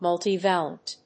アクセント・音節mùlti・válent